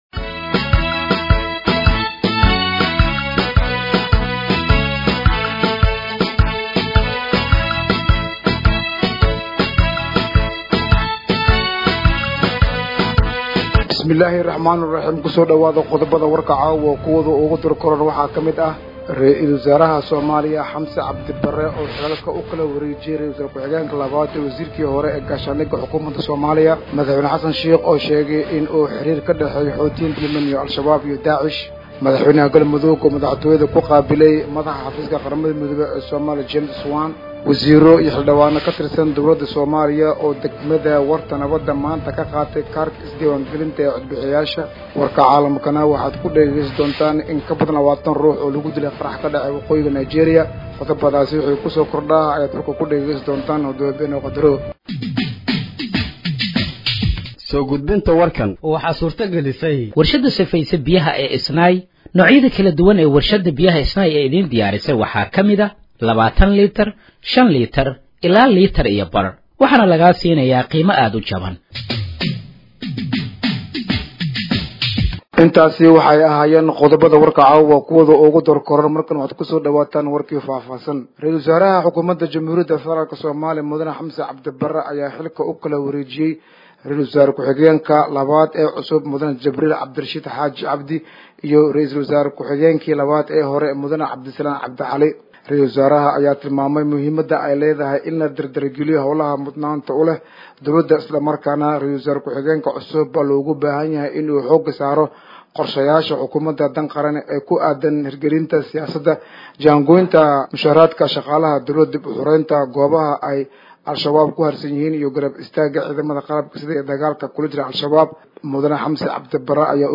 Dhageeyso Warka Habeenimo ee Radiojowhar 29/04/2025